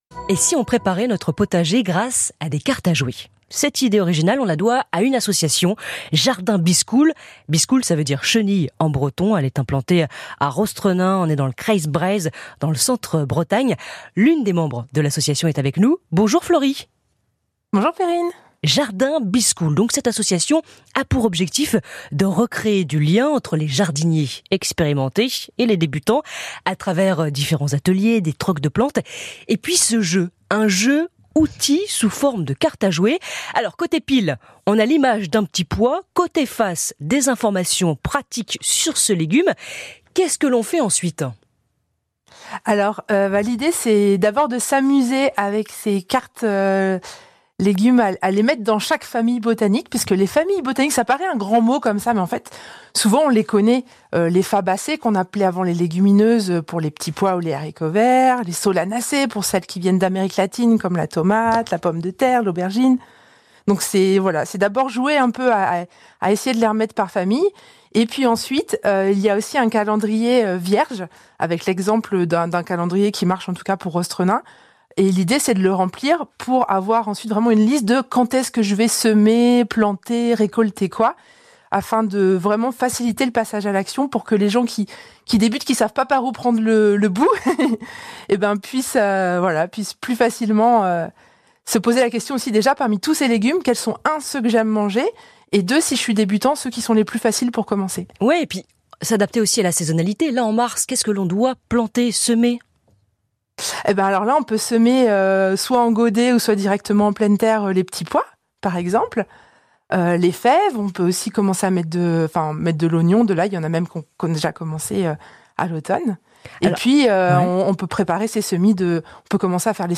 interview-ici-breizh-izel-jardin-biskoul.mp3